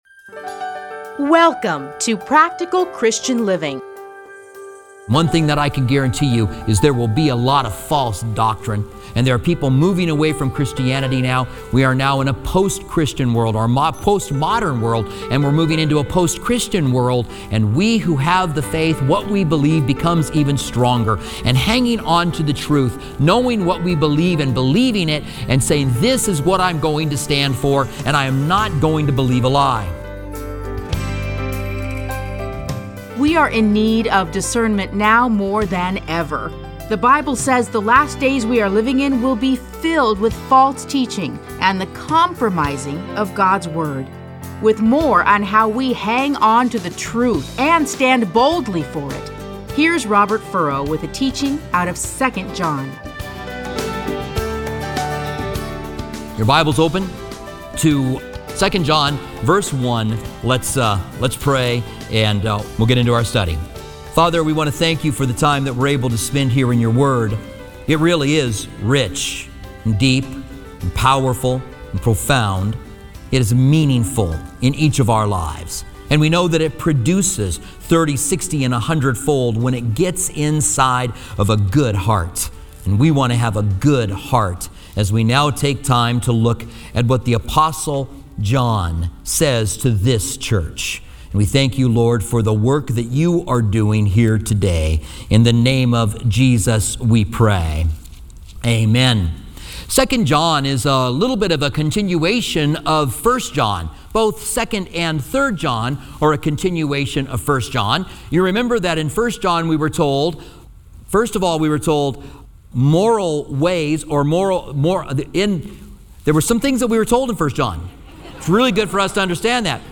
Listen to a teaching from 2 John 1-13.